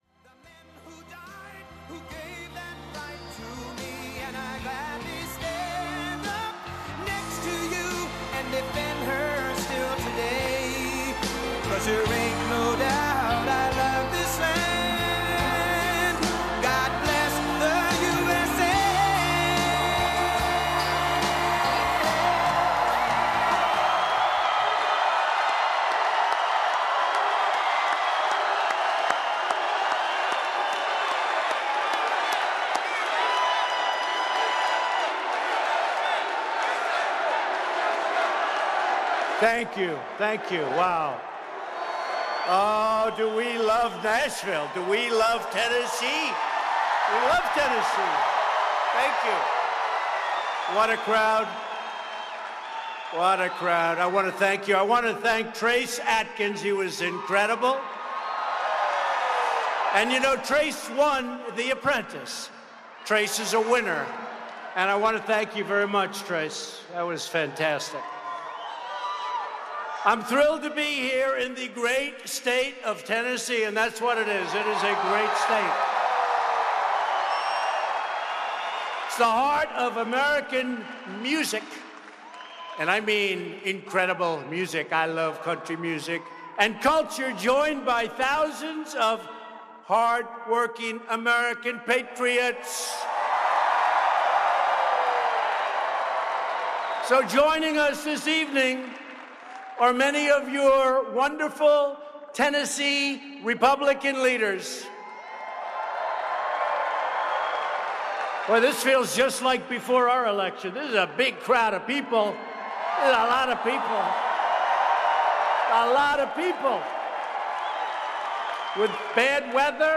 President Trump speaks at a campaign rally for the U.S. Senate campaign of Representative Marsha Blackburn (R-TN). Trump recaps his administration's accomplishments and says voters need to keep the legislature in Republican hands. Trump touches on immigration and the border wall, crime, the economy, trade, and disparages President Obama and Hillary Clinton.
Blackburn thanks Trump for his support at the rally. Held at the Nashville Municipal Auditorium.